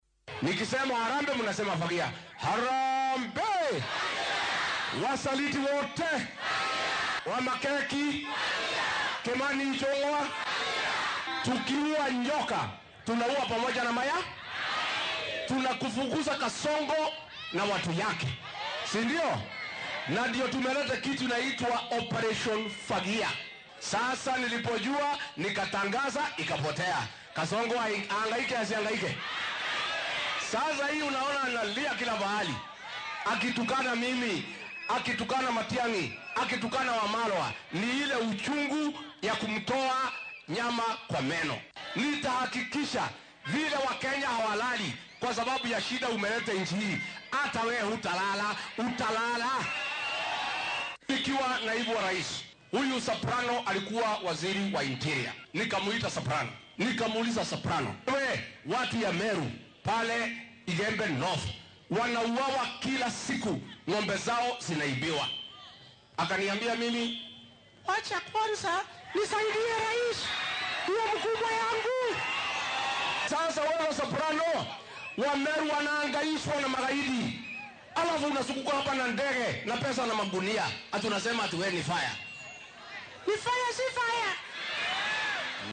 Gachagua, oo ka hadlay deegaanka Githunguri ee ismaamulka Kiambu ayaa sheegay in hadallada adag ee madaxweynaha uu u jeediyay mucaaradka ay ka dhasheen kaliya muujinta khaladaadka hoggaanka iyo musuqmaasuqa baahsan, gaar ahaan qeybta caafimaadka.